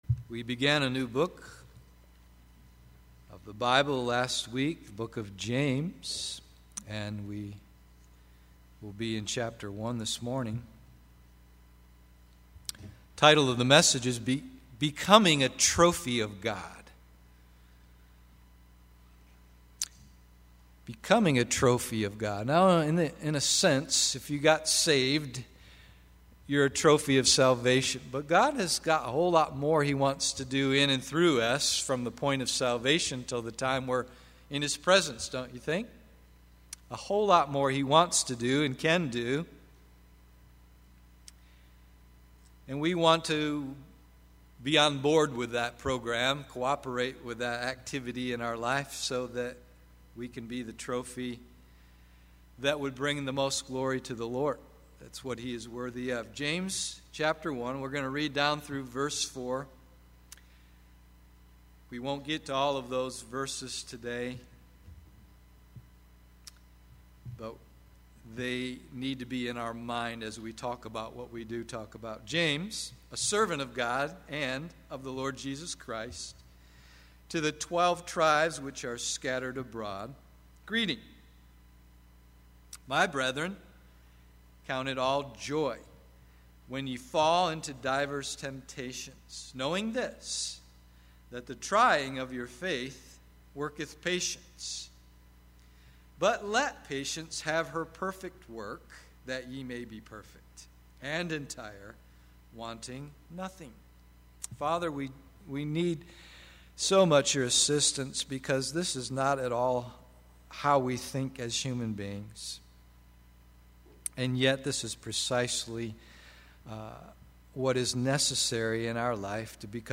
Becoming a Trophy of God AM Service